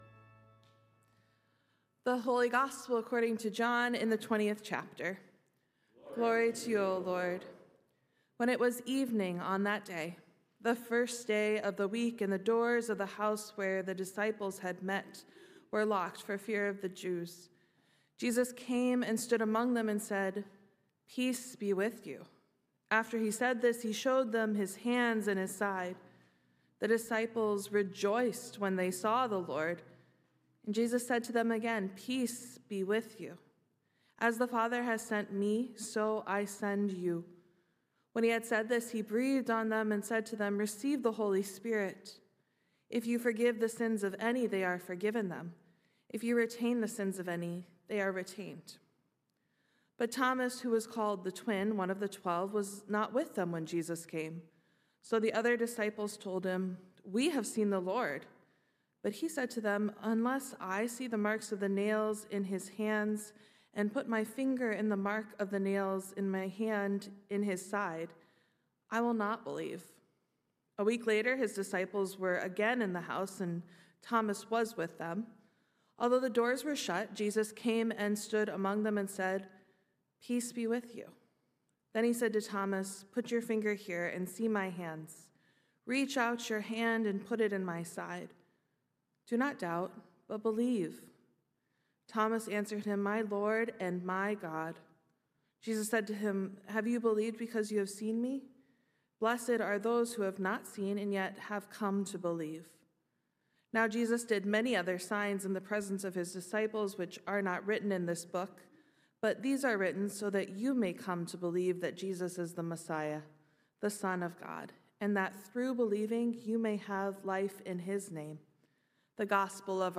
Sermons | Messiah Lutheran Church, Marquette